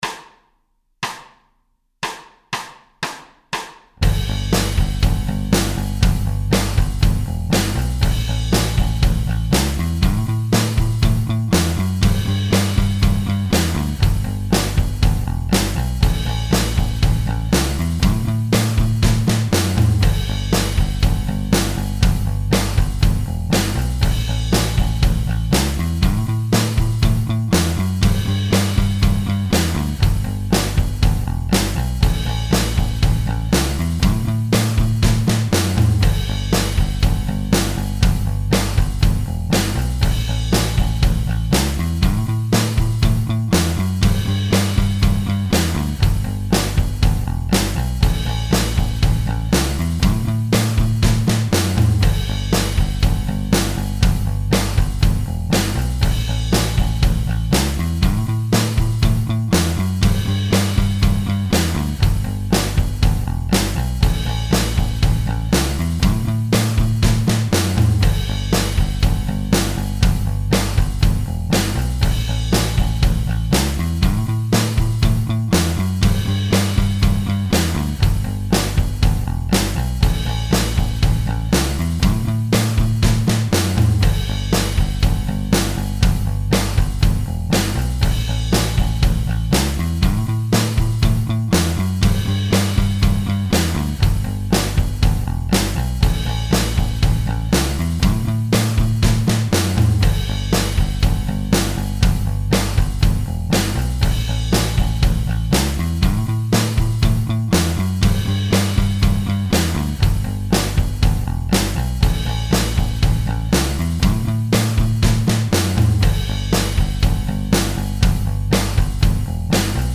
Guitar Lessons: Strumming in Rock